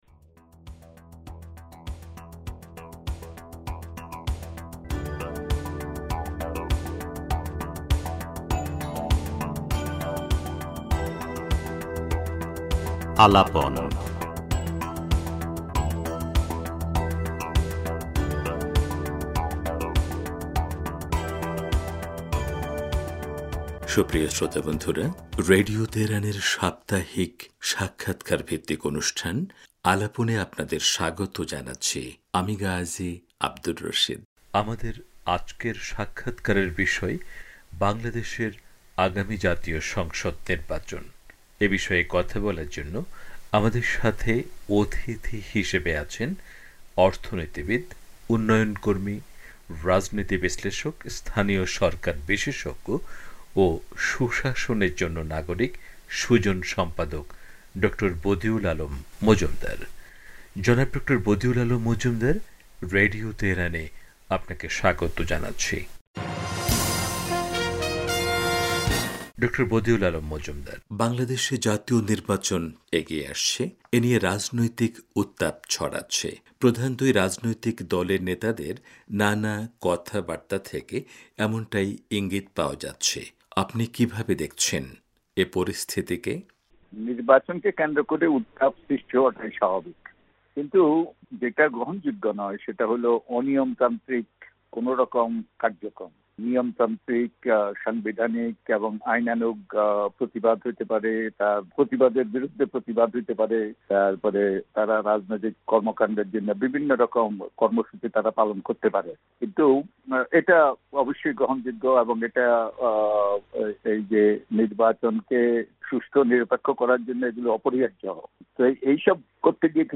পুরো সাক্ষাৎকারটি উপস্থাপন করা হলো।